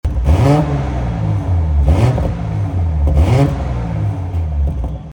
Listen to it's B58 symphony
• Factory Fitted Aftermarket CX Sports Exhaust System With Black Tips (£1,595)